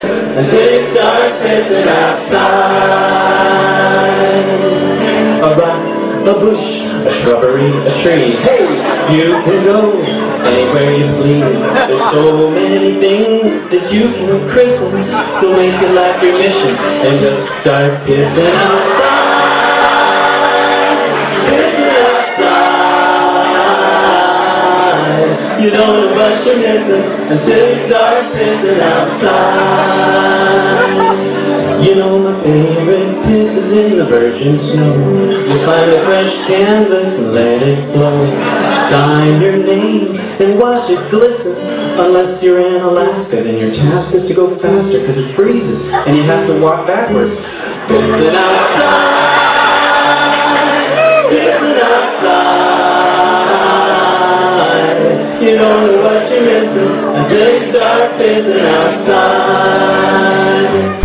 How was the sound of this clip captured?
Concert Pictures and Movies